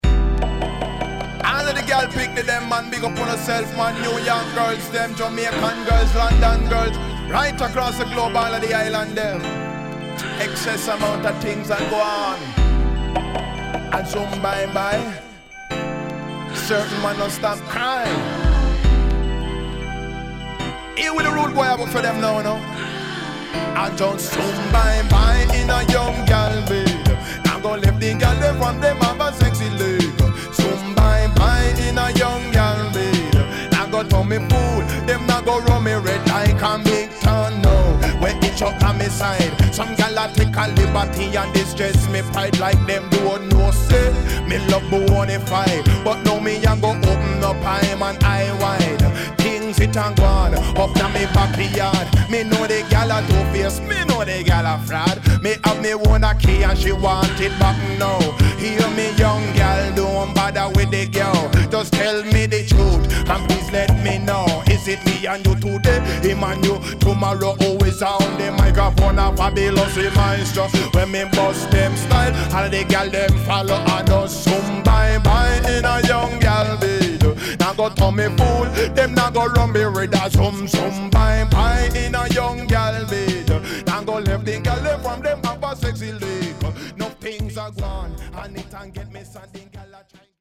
HOME > Back Order [DANCEHALL DISCO45]
SIDE A:少しチリノイズ入ります。